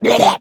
Minecraft Version Minecraft Version 25w18a Latest Release | Latest Snapshot 25w18a / assets / minecraft / sounds / entity / shulker / ambient1.ogg Compare With Compare With Latest Release | Latest Snapshot